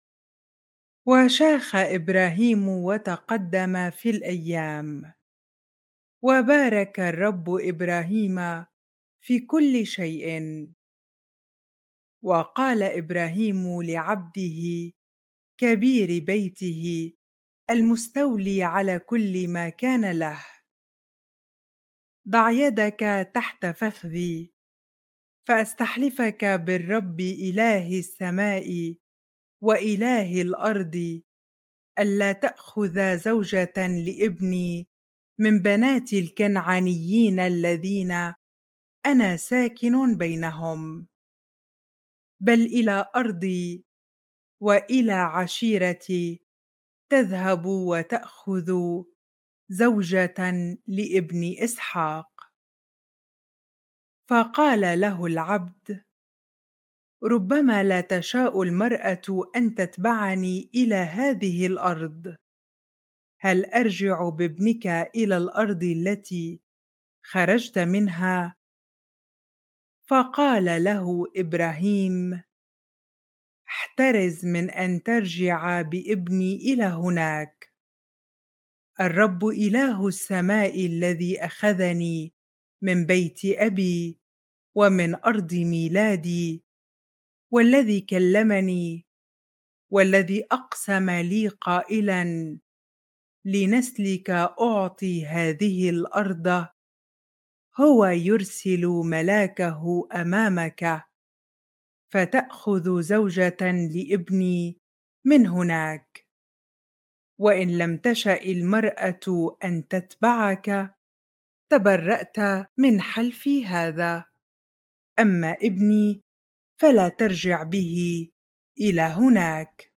bible-reading-genesis 24 ar